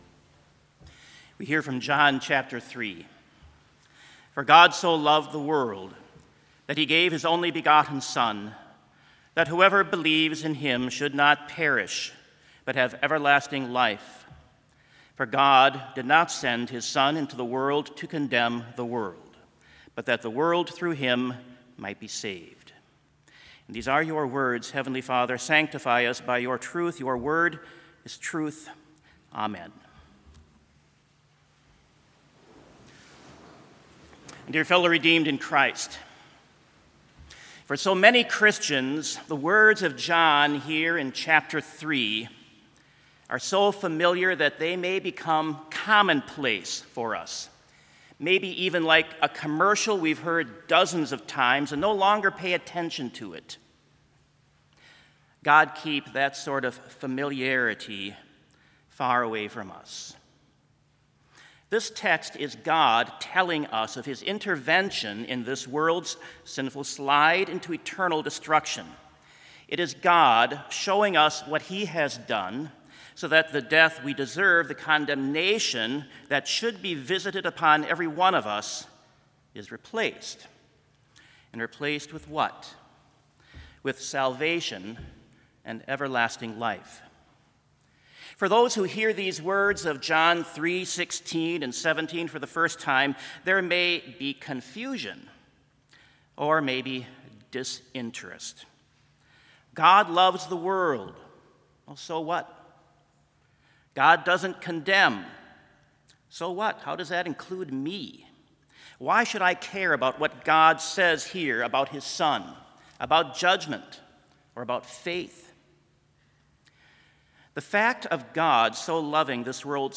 Sermon Only
This Chapel Service was held in Trinity Chapel at Bethany Lutheran College on Tuesday, August 31, 2021, at 10 a.m. Page and hymn numbers are from the Evangelical Lutheran Hymnary.